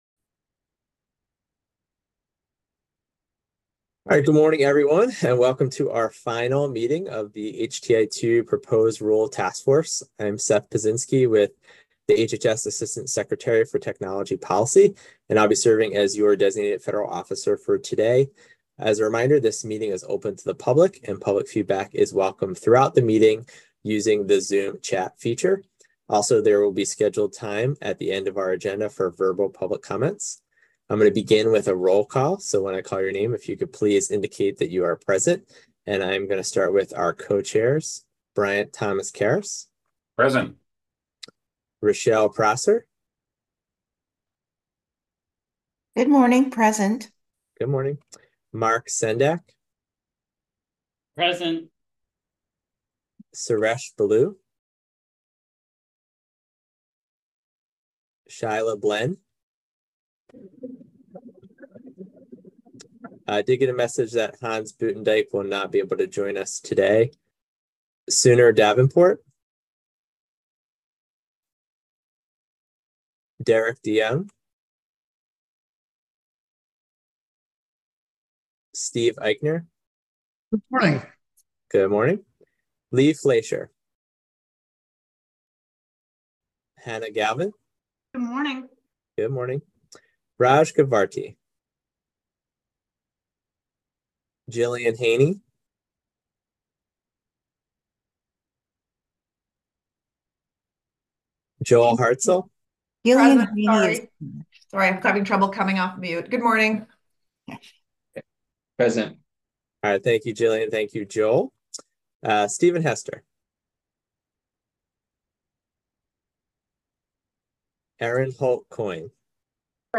HTI-2 Proposed Rule Task Force Meeting Audio 9-5-2024